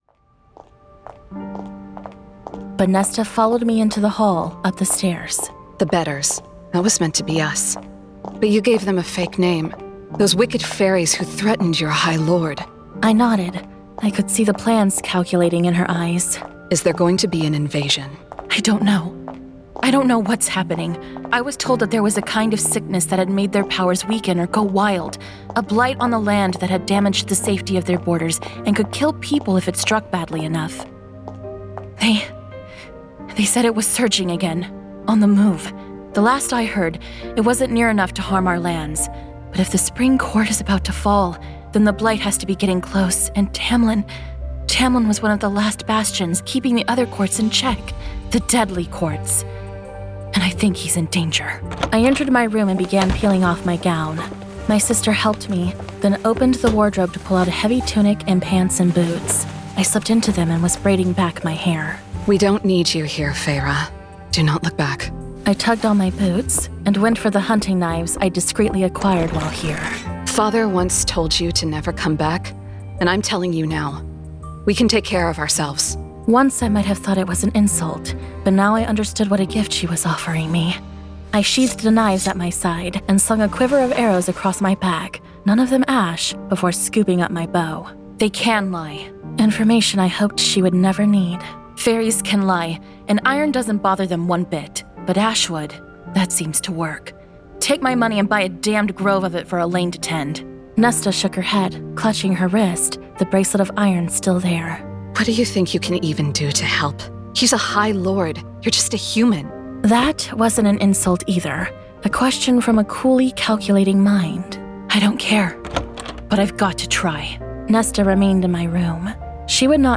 Full Cast. Cinematic Music. Sound Effects.
Genre: Fantasy Romance
Adapted from the novel and produced with a full cast of actors, immersive sound effects and cinematic music!